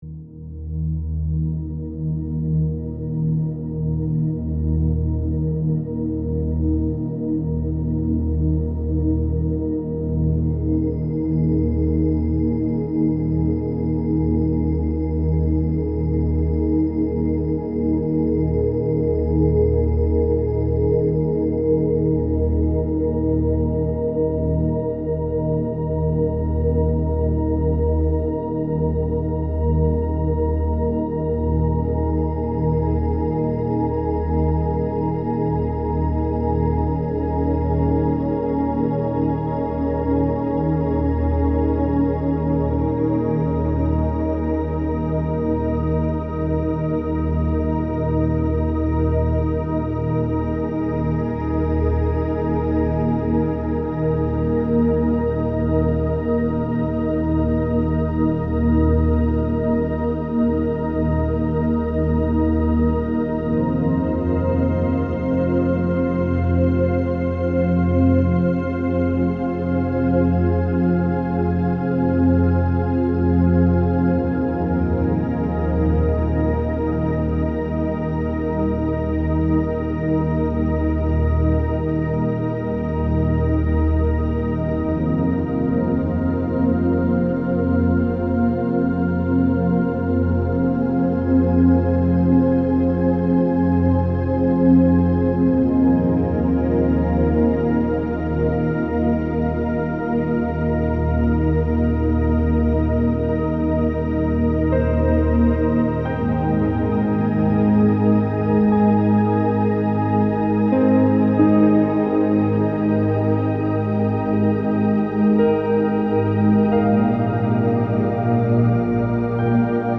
Podprahová hudba z této lekce ke staženi zdarma.